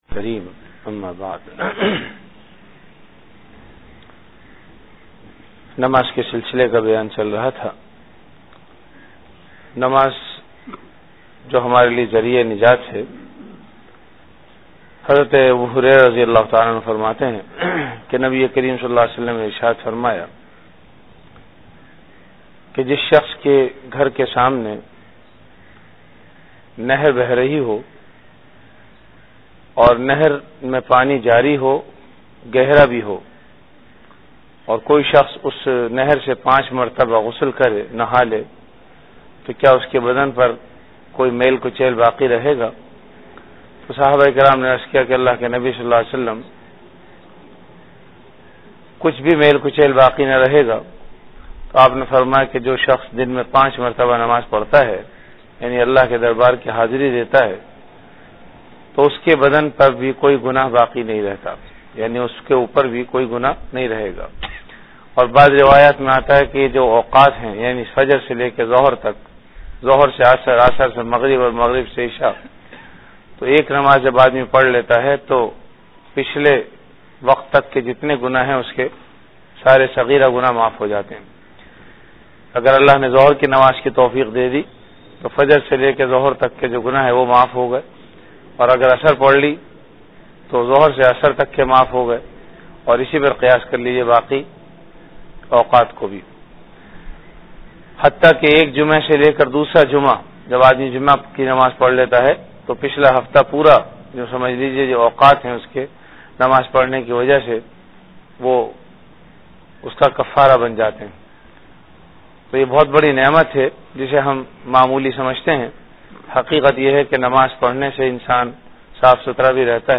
Ramadan - Dars-e-Hadees · Jamia Masjid Bait-ul-Mukkaram, Karachi
CategoryRamadan - Dars-e-Hadees
VenueJamia Masjid Bait-ul-Mukkaram, Karachi
Event / TimeAfter Fajr Prayer